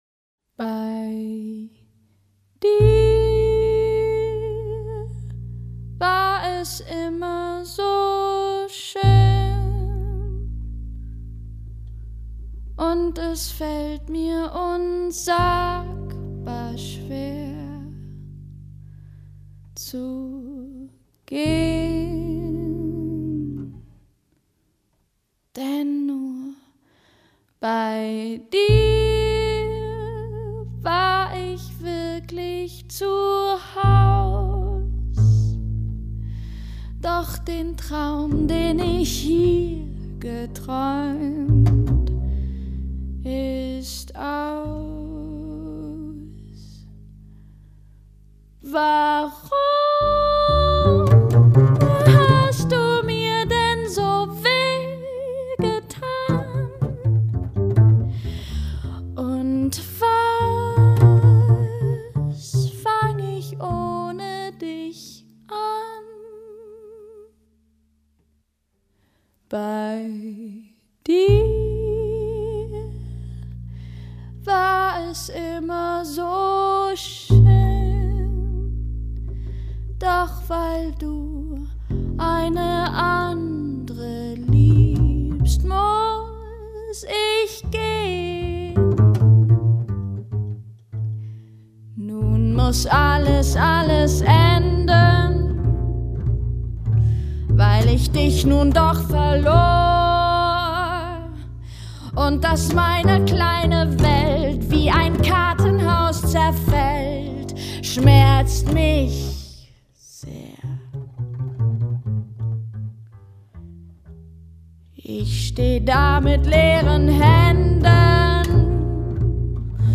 Sopran
Kontrabass